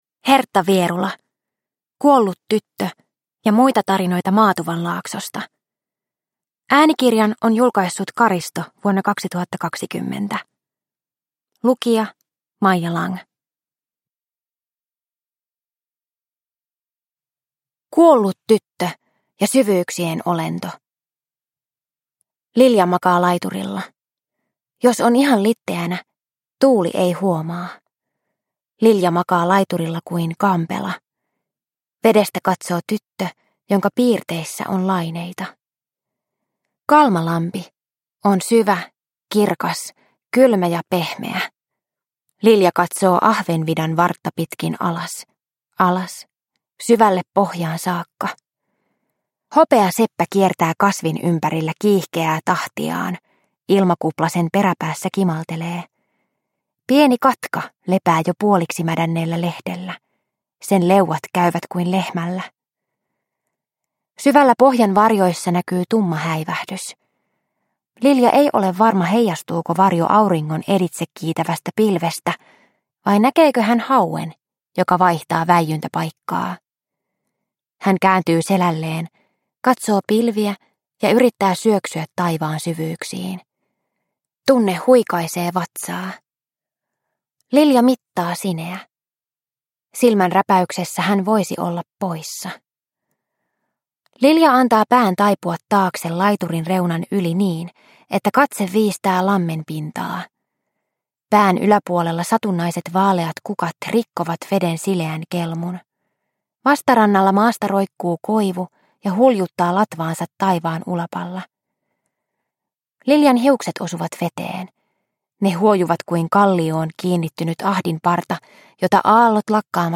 Kuollut tyttö ja muita tarinoita Maatuvanlaaksosta – Ljudbok – Laddas ner